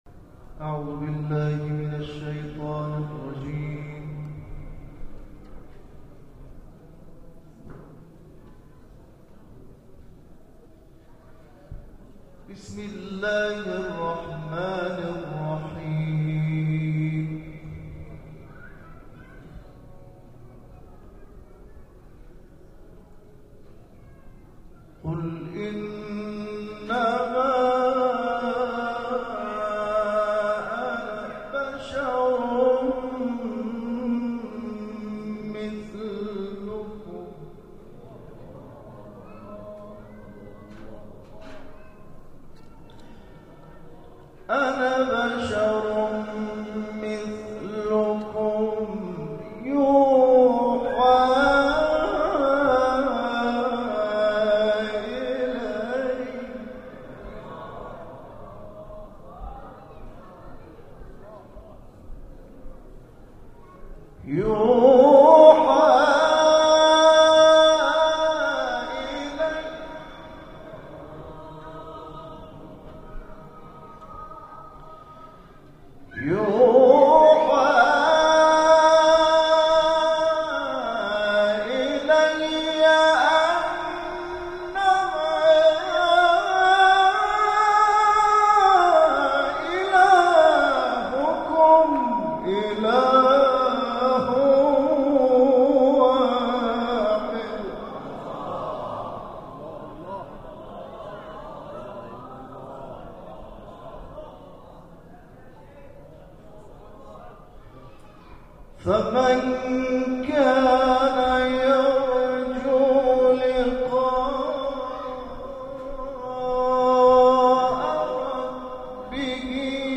تلاوت 3 قاری بین‌المللی در کنار مضجع شریف ثامن الحجج(ع) + صوت
به گزارش خبرگزاری بین‌المللی قرآن(ایکنا) به مشهد مقدس، دومین محفل قرآنی کاروان قرآنی ۸۰ نفری فرهنگسرای قرآن با حضور اساتید و قاریان این کاروان، در دارالقرآن حرم مطهر امام رضا(ع)، در جوار مضجع شریف سلطان طوس، از ساعت 19 الی 20:30 برگزار شد.
تلاوت